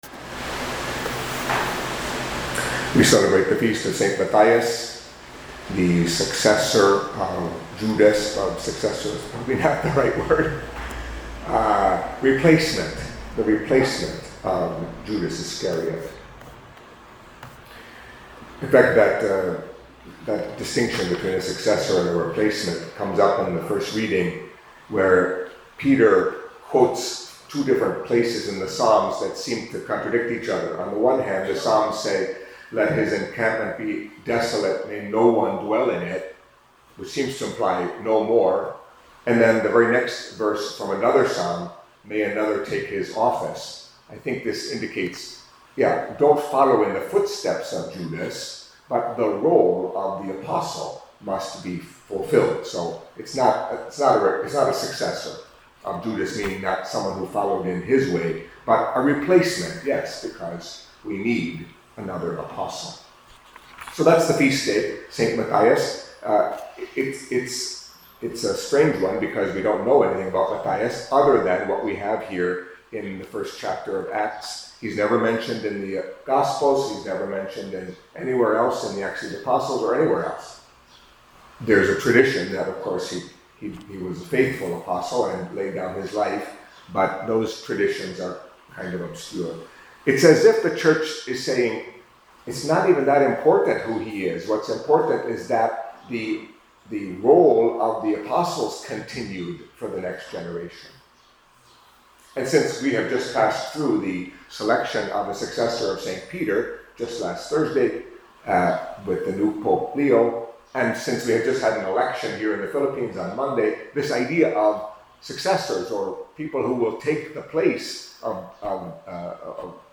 Catholic Mass homily for Feast of Saint Matthias, Apostle